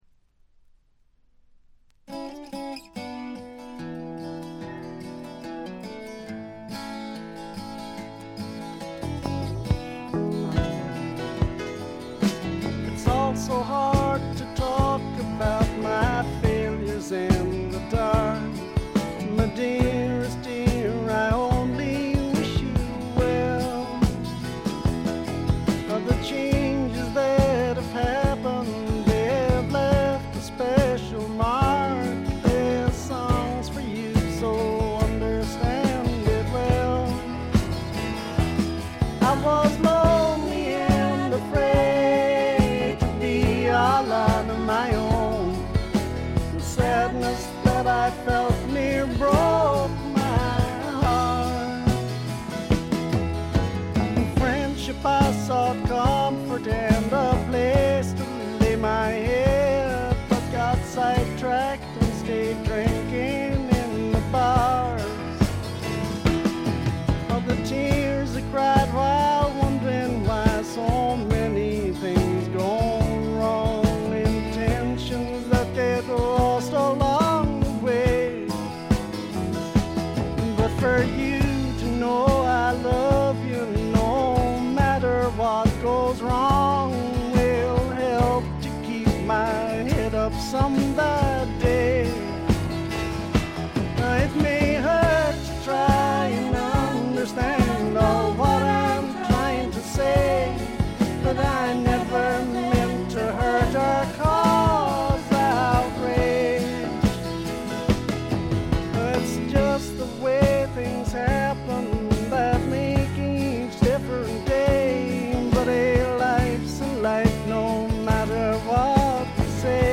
ほとんどノイズ感無し。
ひとことで言って上品で風格のあるフォーク･ロックです。
試聴曲は現品からの取り込み音源です。
Recorded At Marquee Studios